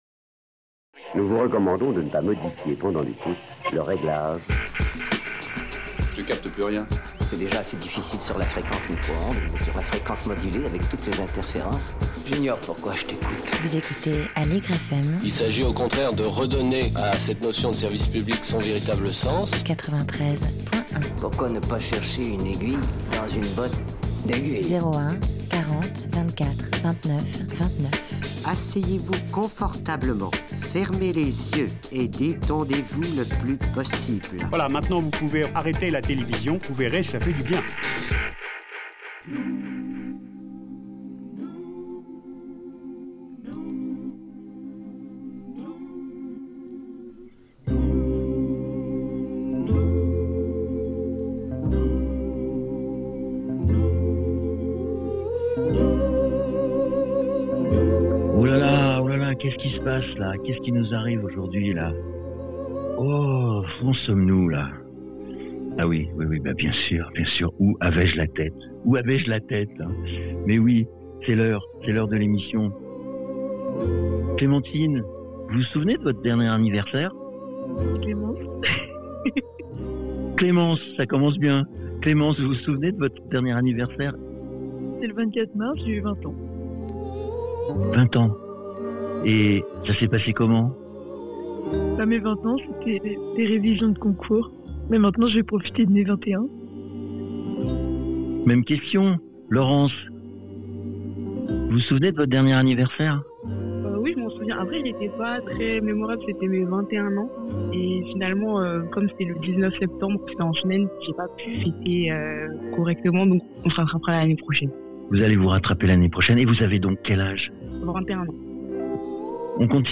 L’Étincelle dans la ville est allée à la rencontre de 5 jeunes femmes qui nous ont raconté comment ça se déroule pour elles lorsque l’on a 20 ans en 2026, aussi bien sur leur façon de vivre que sur des thèmes plus généraux comme l’emploi les loisirs, ou leur vie de tous les jours :